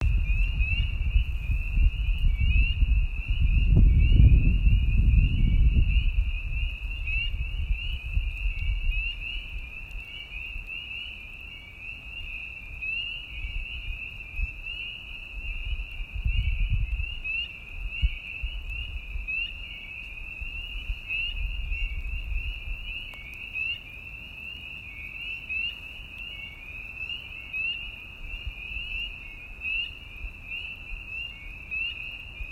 On warmer nights the air fills with the high-pitched voices of tiny spring peepers whose many calls blend into a ringing chorus that drifts well beyond the lake.
Peepers Chorus.m4a